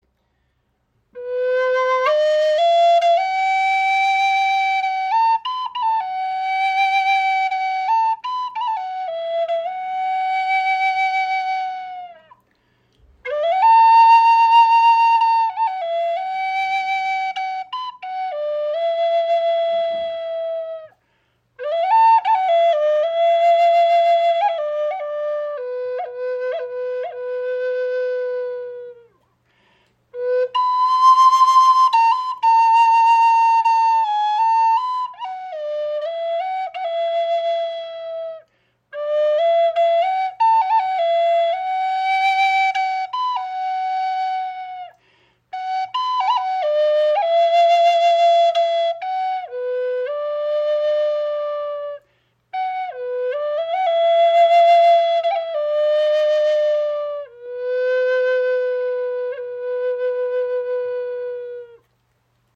• Icon Handgefertigte 432 Hz Flöte aus edlem Holz für klare, warme Klänge
Mit warmer, klarer Stimme, Regenbogen-Mondstein, 432 Hz Gravur und Totem.
Das dichte Holz verleiht ihr eine klare, warme und zugleich kräftige Klangstimme, die besonders tragfähig ist.